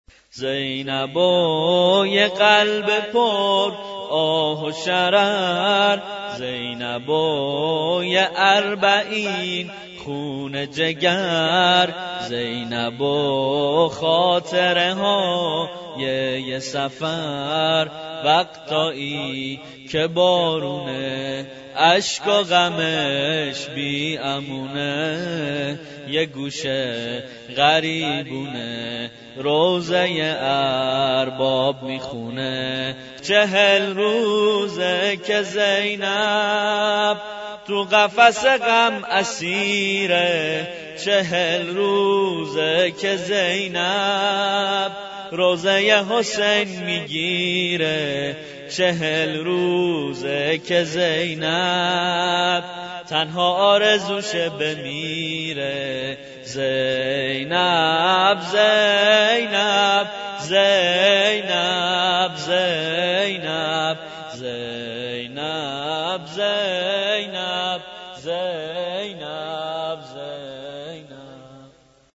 (به سبك زینب و یه قلب پر)